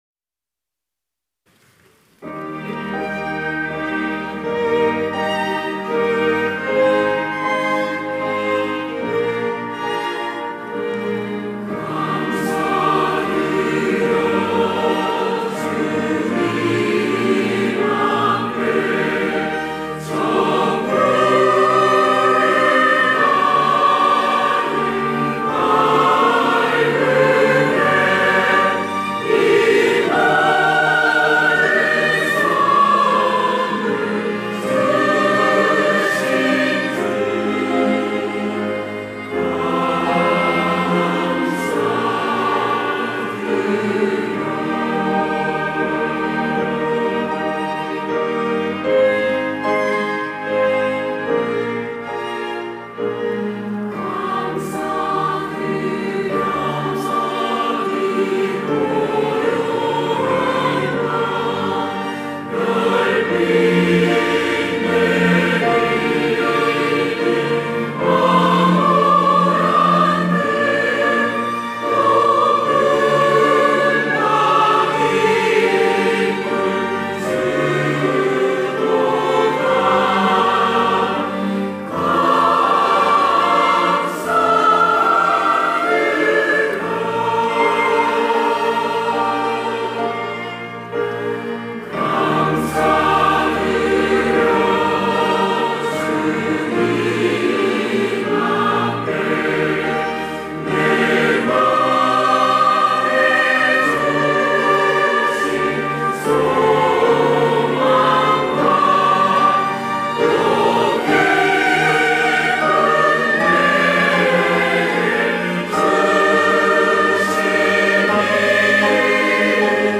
호산나(주일3부) - 감사드려
찬양대 호산나